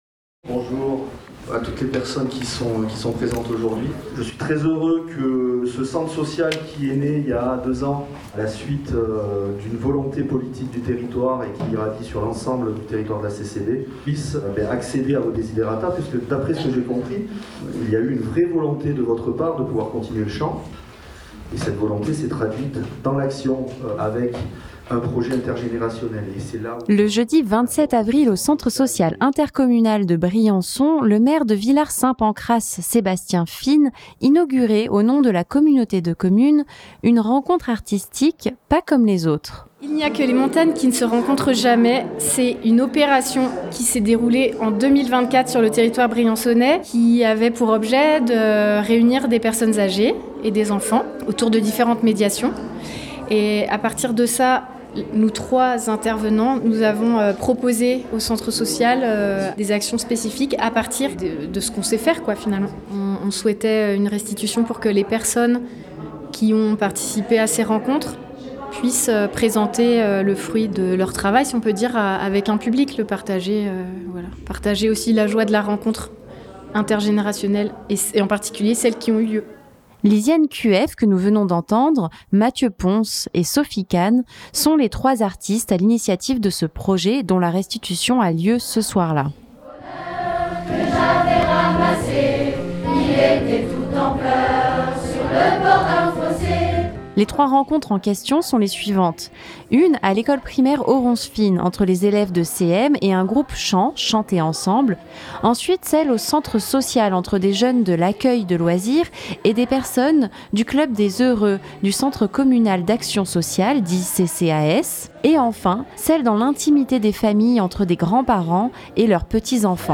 Reportage.